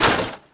gibmetal1.wav